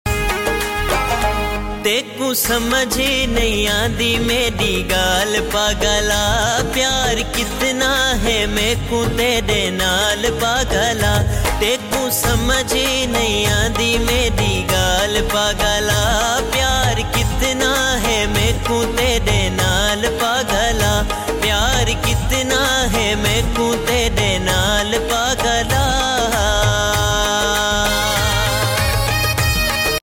saraiki song